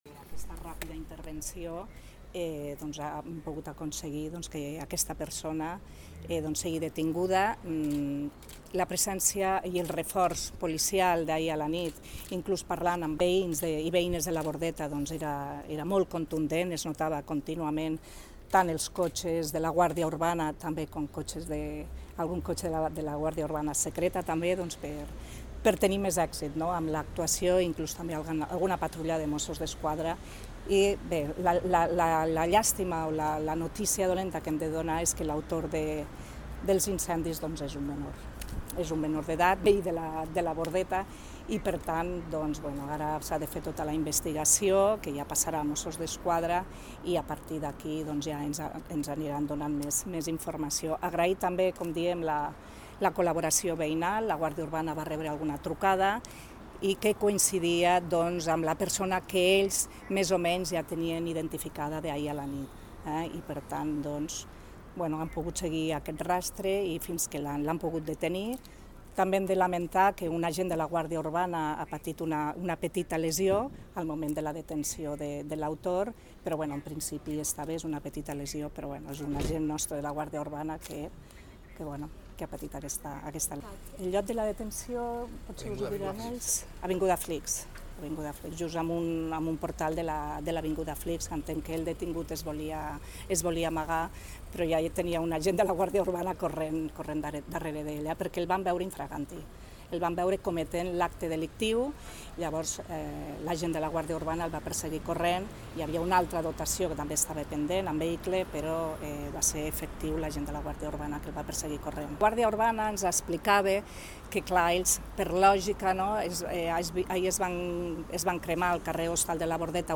Ampliación de información La alcaldesa accidental ha comparecido ante los medios de comunicación este mediodía, en el cuartel de la Guardia Urbana. (Audio adjunto de la intervención).